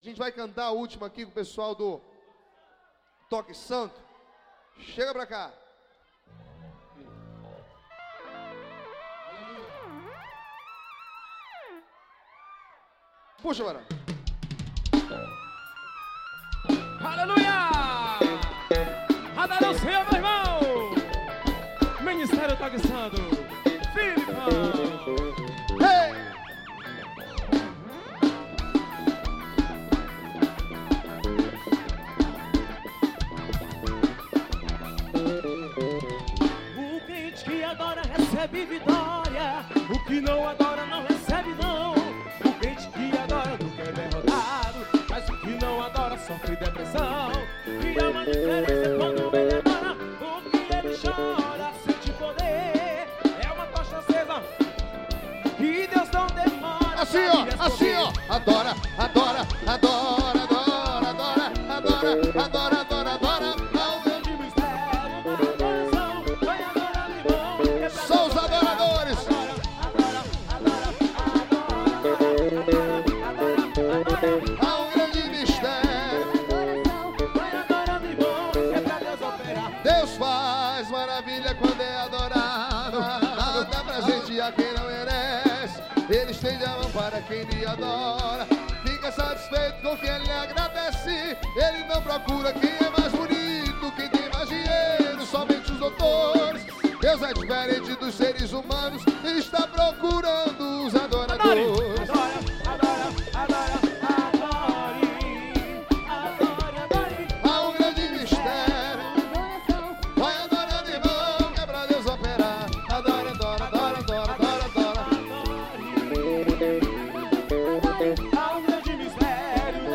Forro.